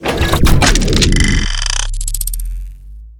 laserin.wav